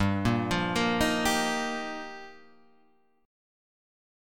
Gm7#5 chord {3 6 5 3 6 3} chord